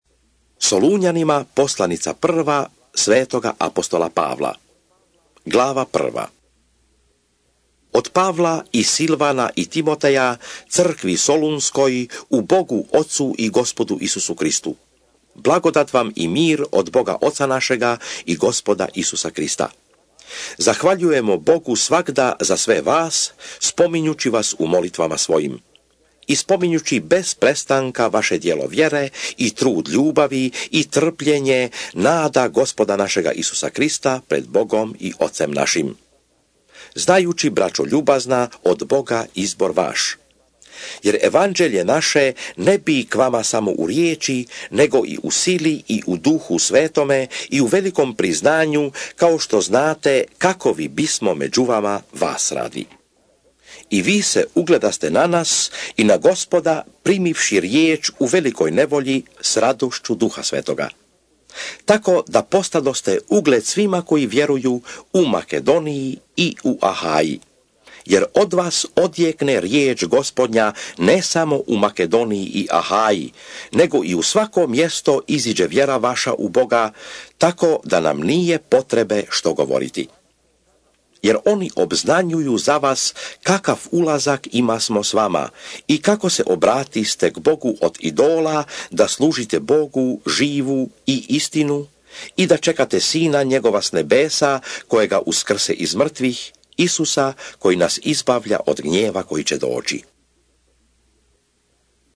1 SOLUNJANI(ČITANJE) - Bible expounded